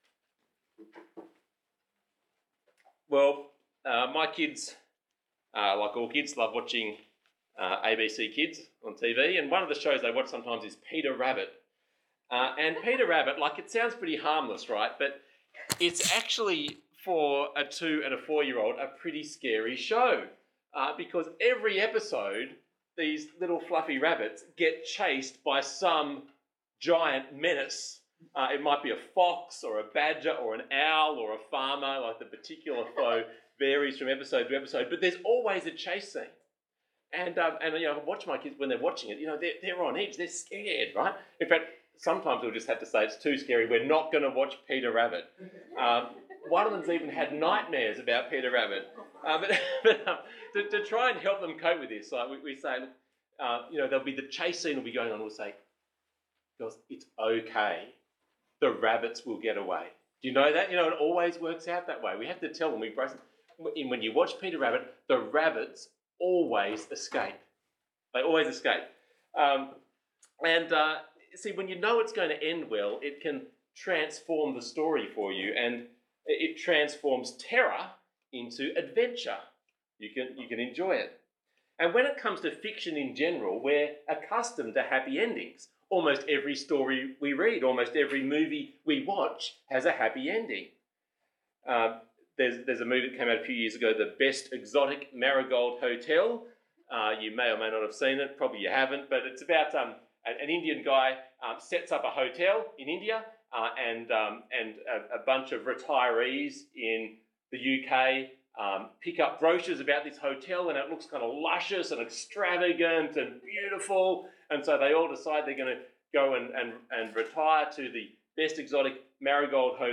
Bible Talk